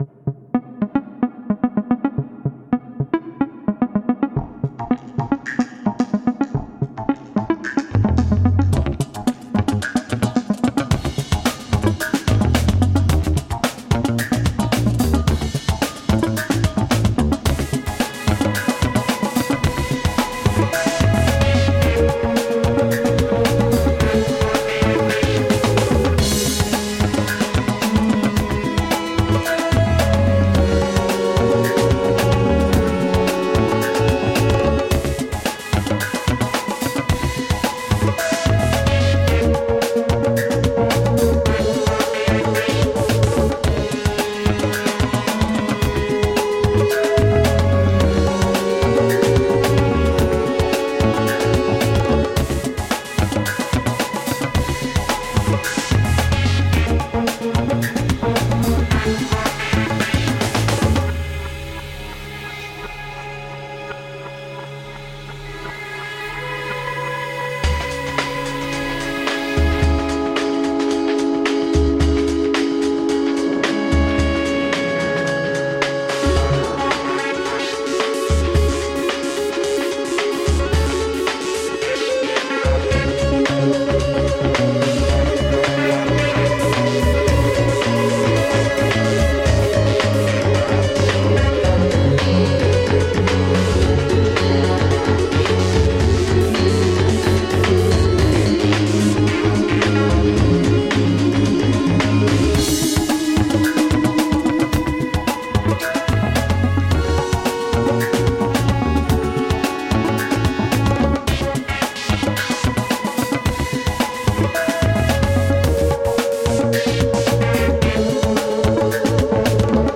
Groove laden chillout funk.
Tagged as: Jazz, Funk, Chillout